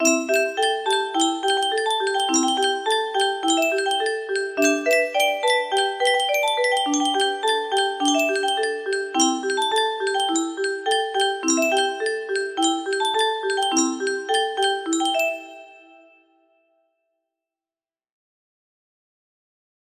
Marguerite (15) music box melody